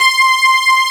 14 STRG C5-R.wav